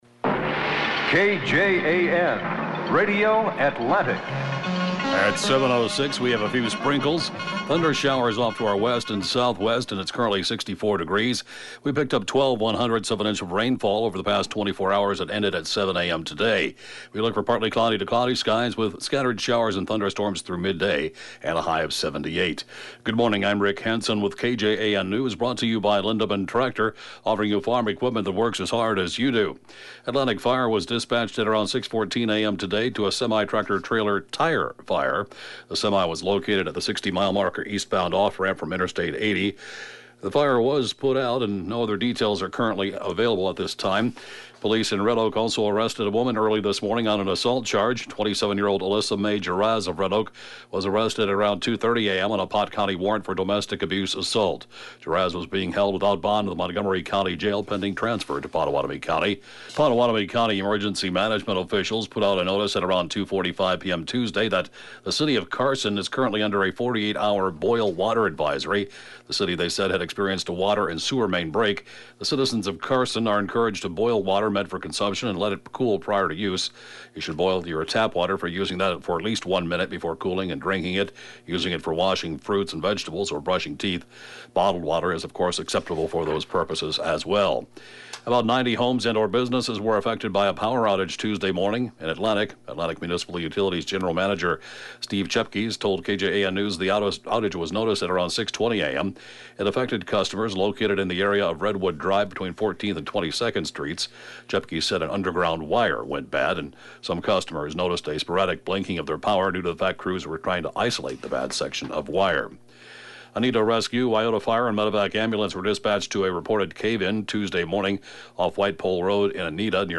(Podcast) KJAN Morning News & funeral report, 4/12/2018